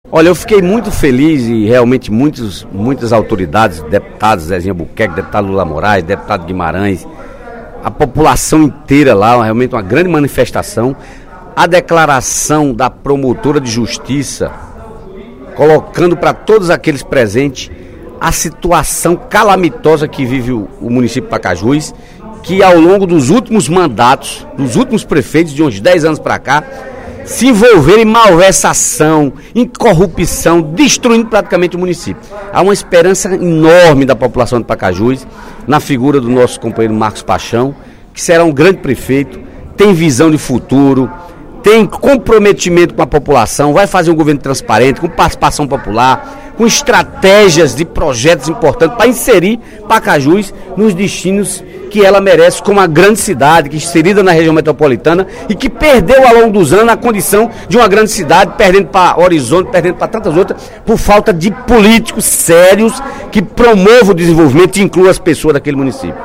O deputado Dedé Teixeira (PT) fez pronunciamento durante o primeiro expediente desta sexta-feira (14/12), na Assembleia Legislativa, para destacar a diplomação na cidade de Pacajus, do novo prefeito do município, Marcos Paixão, do PT, e de seu vice, Tintim Menezes, do PSB.